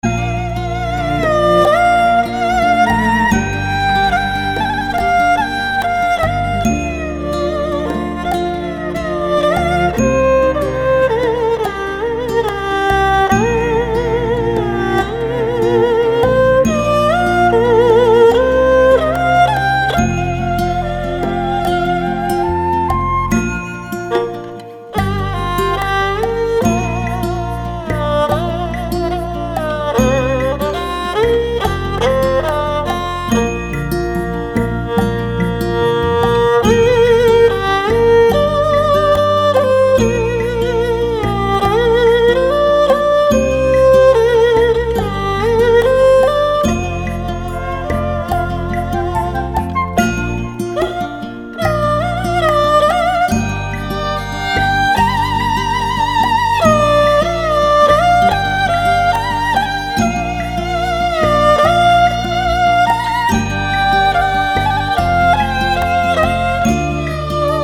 中國音樂、新世紀音樂